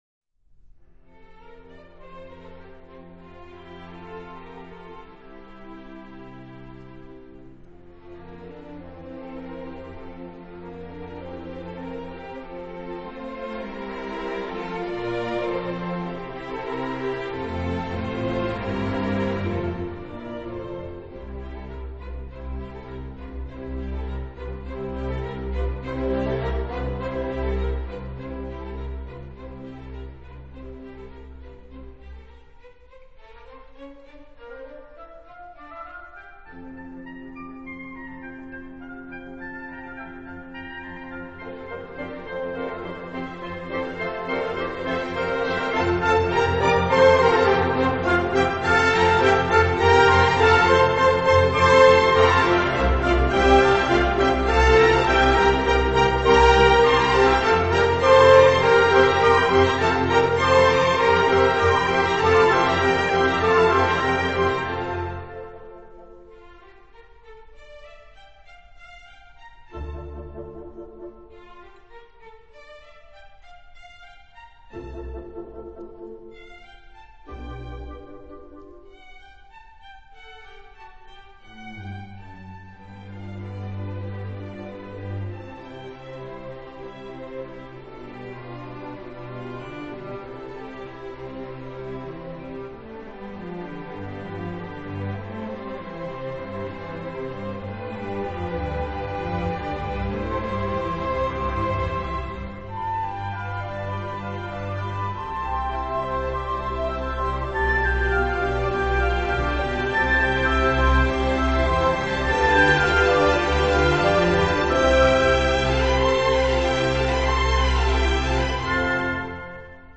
这版本有黑胶片的温润的效果 音韵厚实 音场宽扩